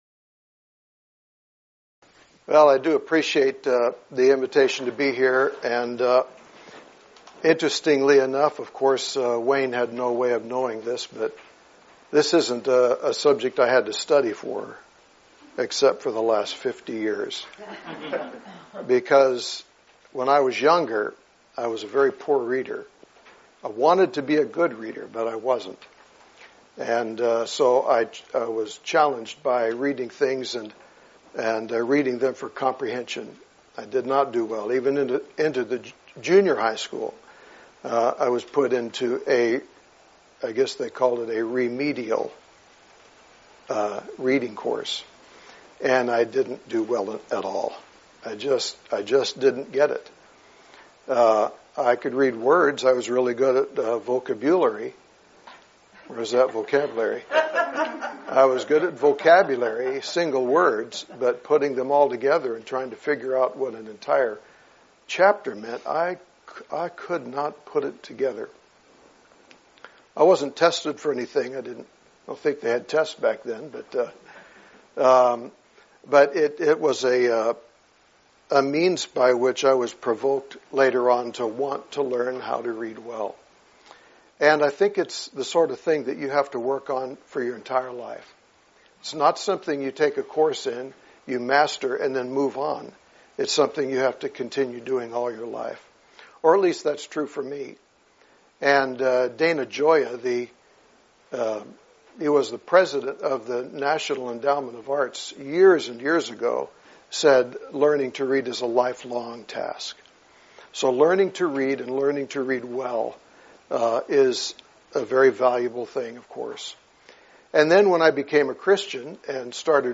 Event: 2019 Focal Point
lecture